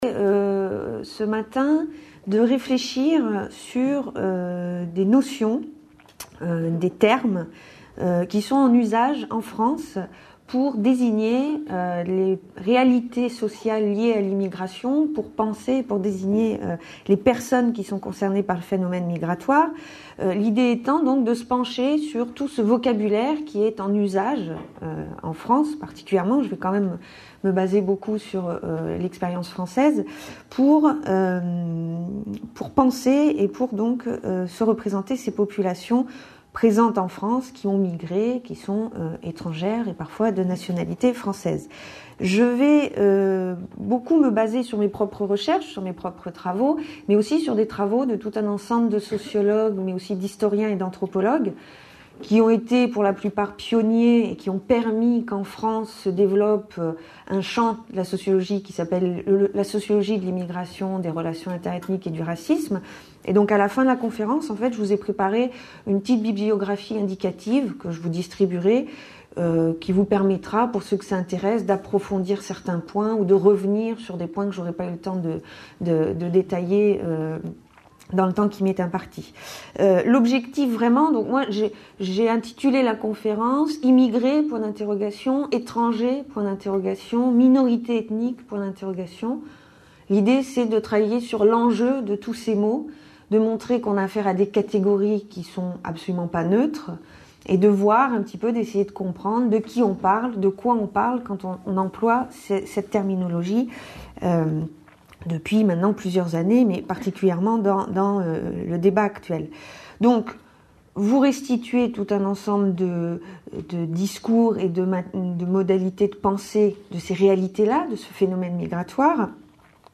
Une conférence de l'UTLS au Lycée Immigrés